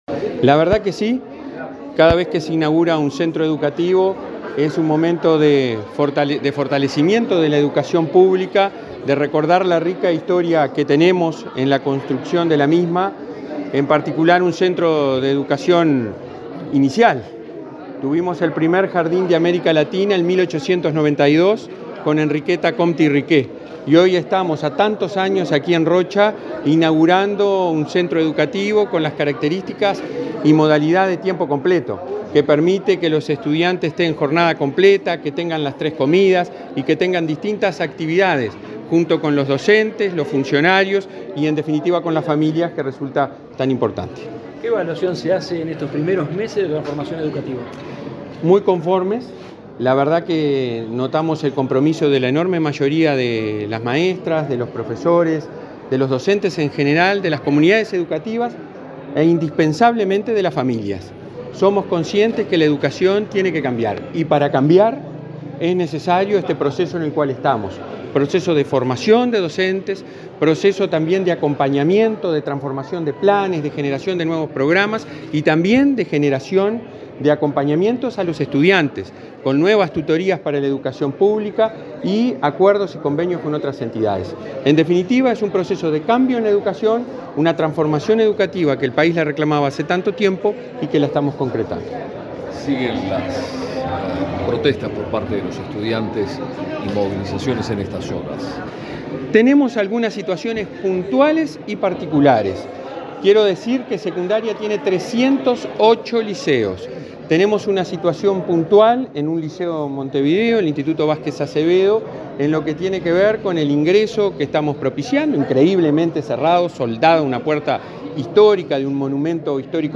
Declaraciones del presidente de ANEP, Robert Silva
Luego dialogó con la prensa.